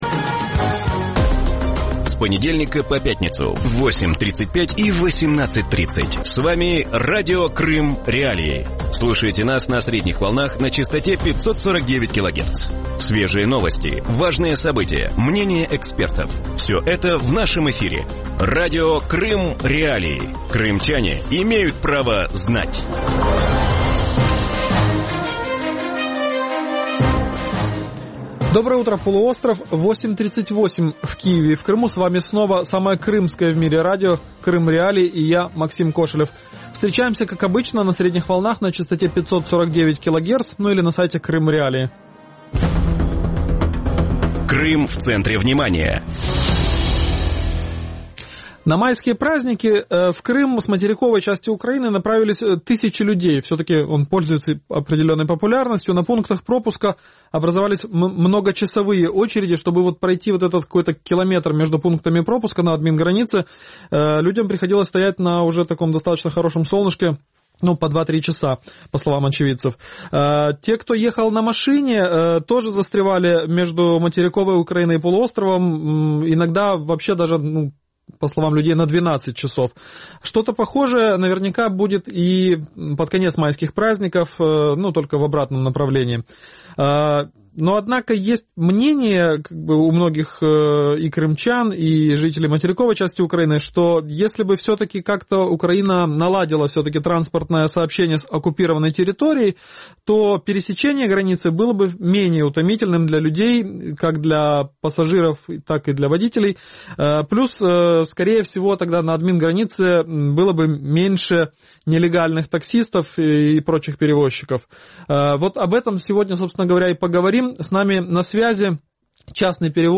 Утром в эфире Радио Крым.Реалии говорят о нелегальных перевозках пассажиров через административную границу с Крымом.